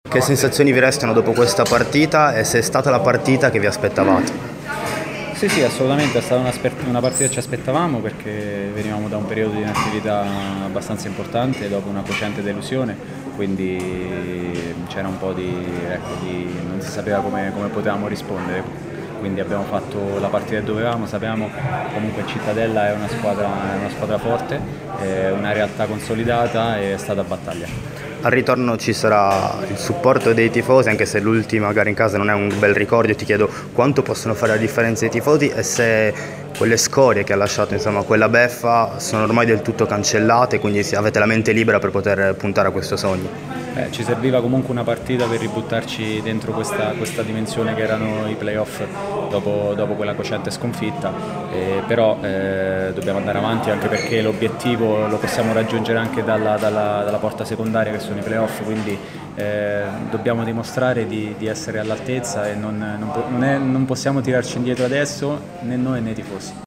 Nel post gara di Cittadella-Frosinone, andata della semifinale play-off di Serie B terminata 1-1
intervista in esclusiva a RMC Sport